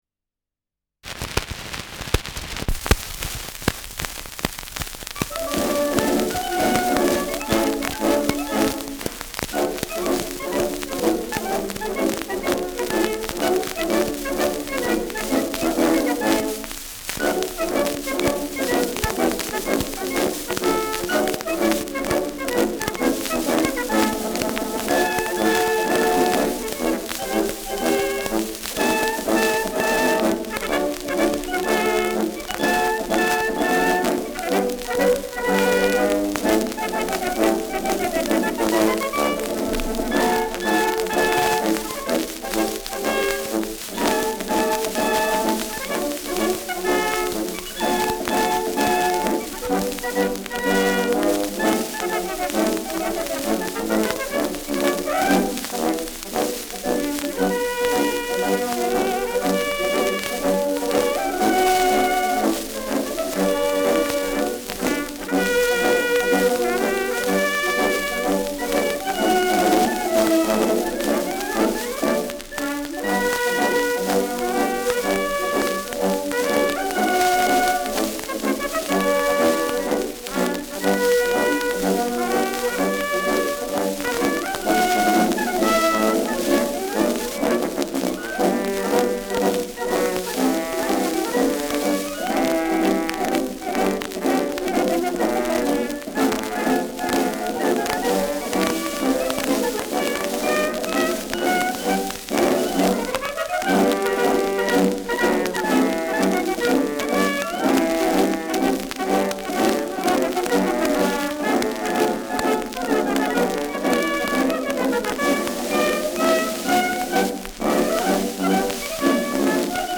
Schellackplatte
Schützenkapelle* FVS-00006